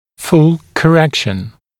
[ful kə’rekʃ(ə)n][фул кэ’рэкш(э)н]полная коррекция